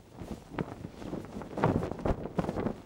cloth_sail9.L.wav